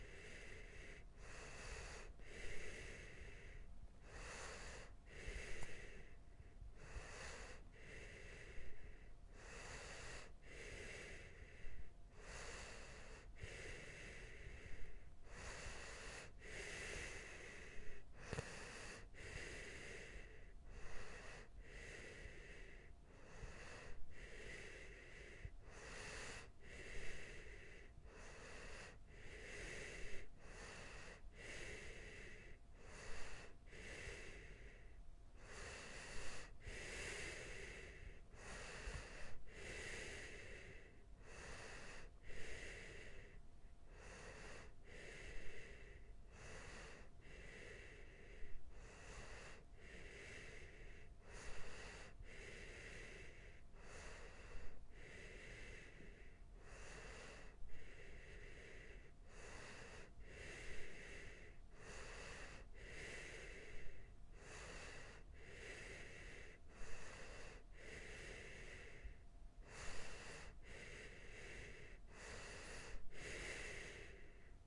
Tag: 呼吸 睡眠 snorring